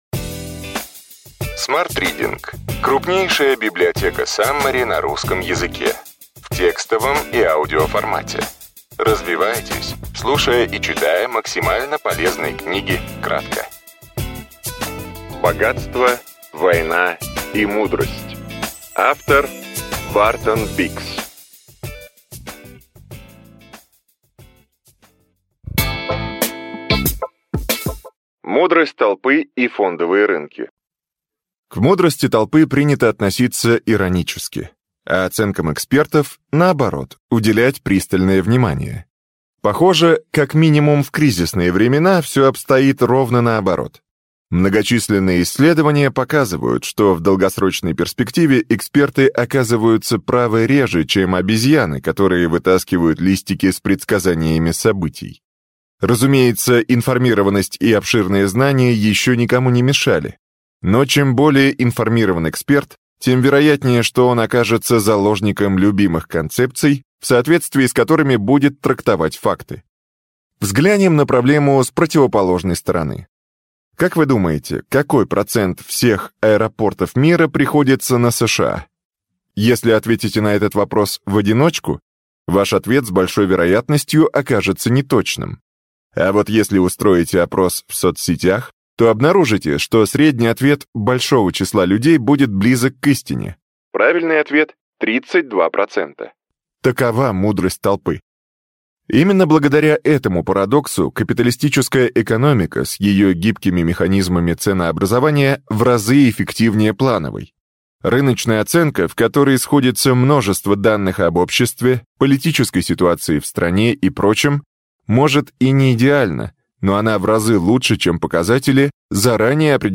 Аудиокнига Ключевые идеи книги: Богатство, война и мудрость. Бартон Биггс | Библиотека аудиокниг